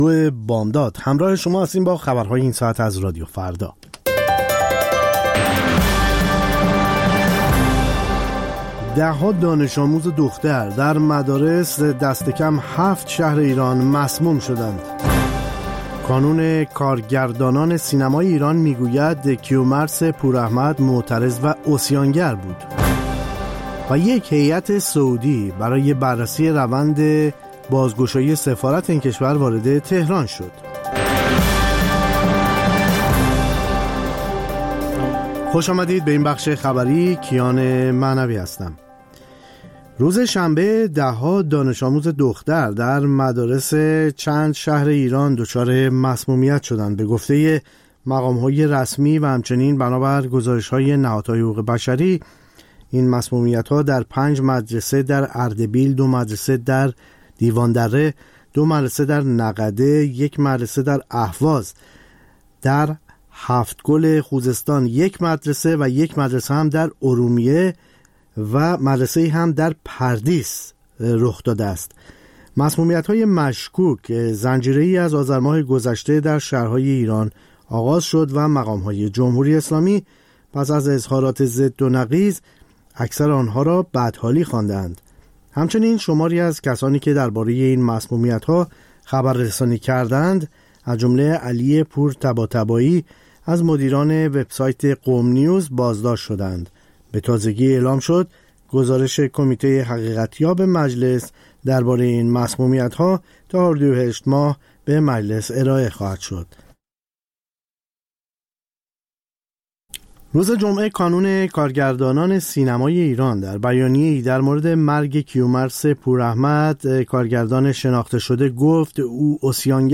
سرخط خبرها ۲:۰۰